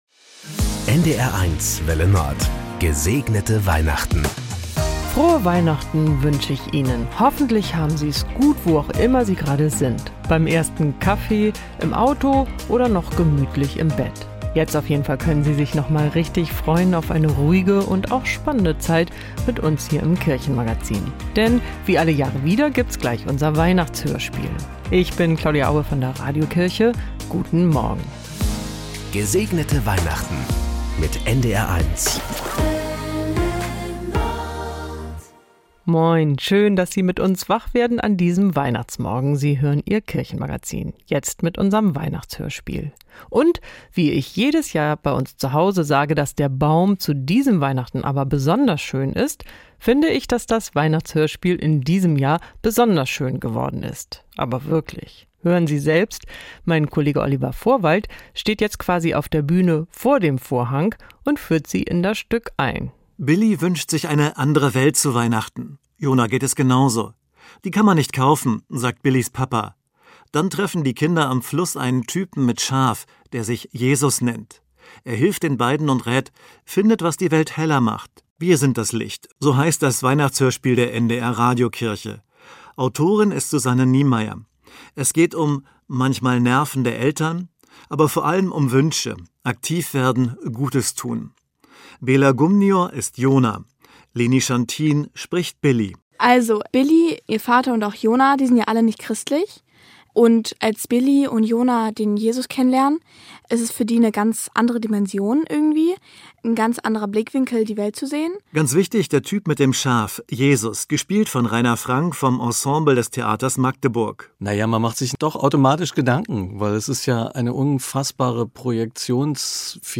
Im Weihnachtshörspiel der Evangelischen Kirche im NDR geht es um